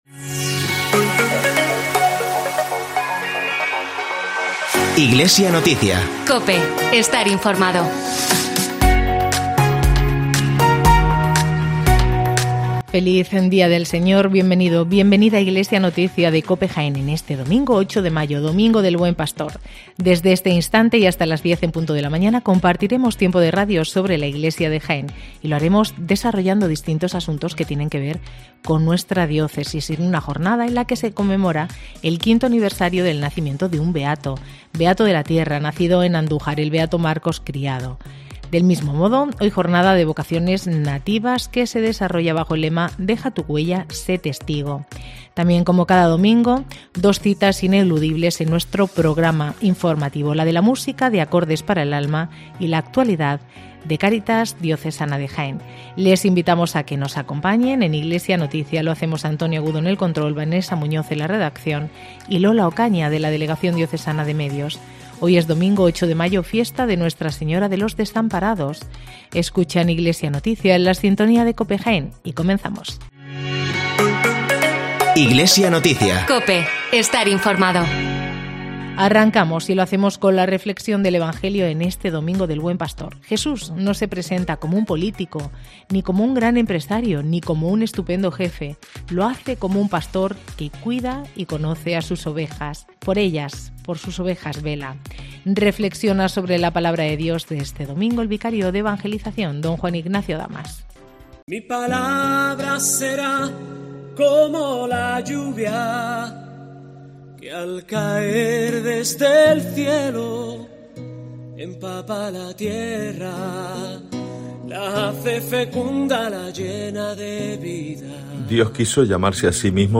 Informativo Iglesia Noticia